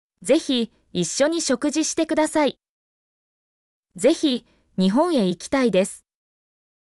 mp3-output-ttsfreedotcom-40_gUp7pO2h.mp3